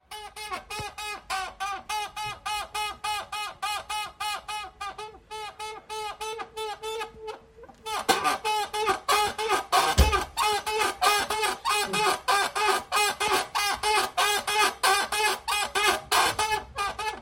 Quietschen (manchmal) Hier eine akustische Repräsentation dieser Ressource: